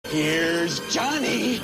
Texture-Pack/assets/minecraft/sounds/mob/zombie/woodbreak.ogg at 81100ee335597a6adb7dbcea80e4eb6fbe2483c5
woodbreak.ogg